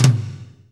TOM F S M16R.wav